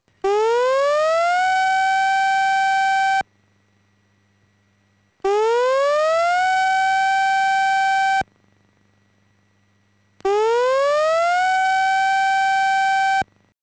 防災行政無線は、地震や津波などの災害時における緊急放送をはじめ、行方不明者の捜索など緊急性の高い情報を、市内に設置している屋外拡声器（スピーカー）を通じて放送しています。
サイレン音3秒吹鳴、2秒休止（3回繰り返し） 「大津波警報。大津波警報。（東日本大震災クラスの津波がきます。）ただちに高台に避難してください。」（3回繰り返し）